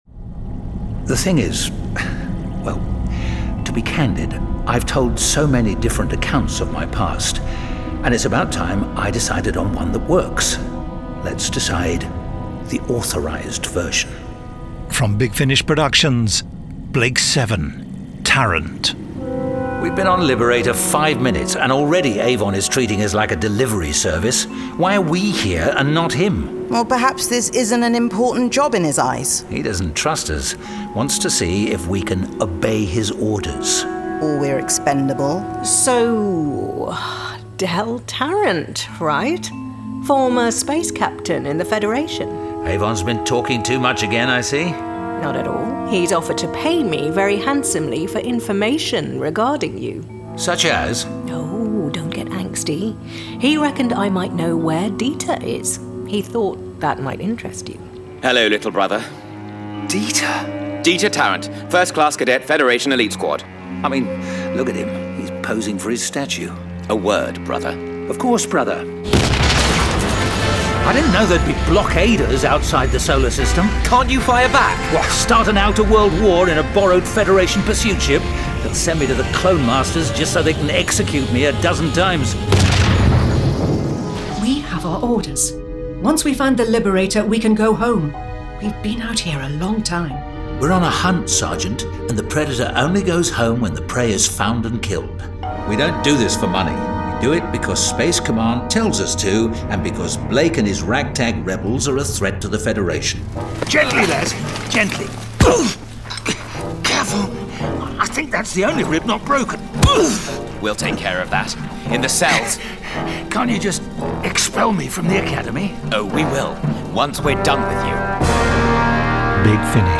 Award-winning, full-cast original audio dramas